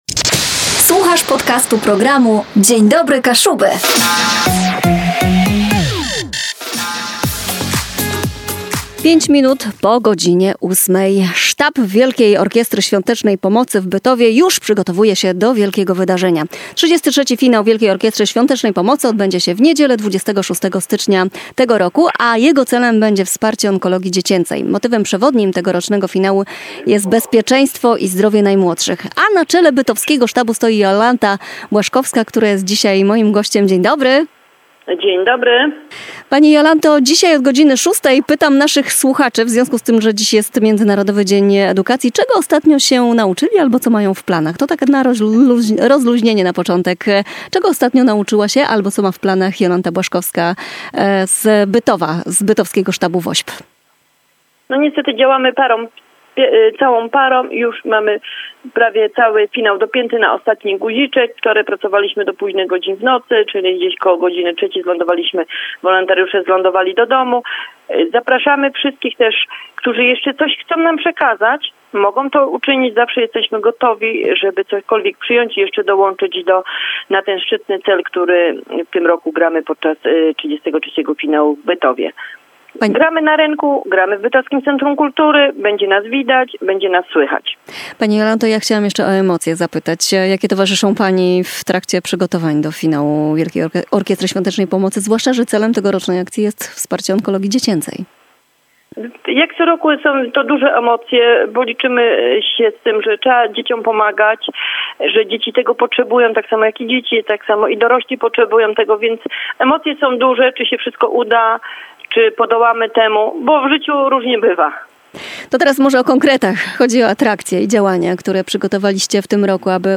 W studiu Radia KASZËBË